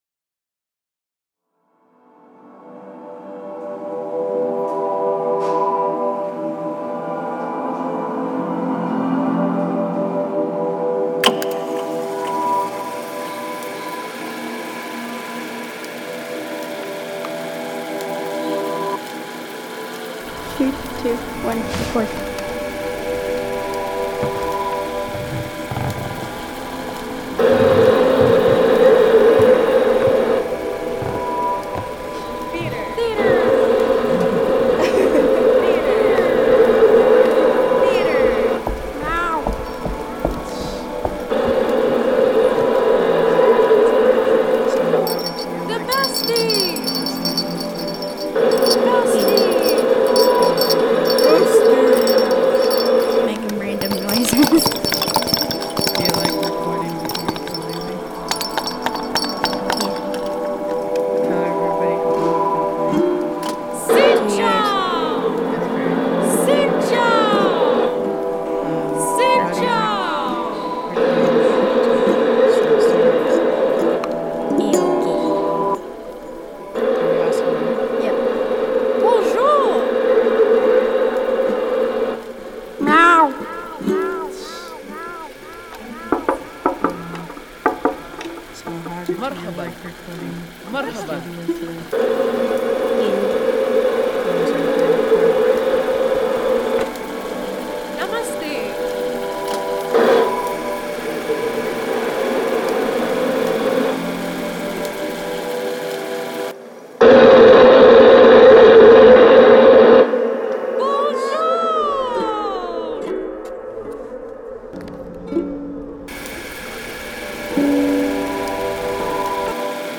Listen: not a town but a landing page workshop soundscape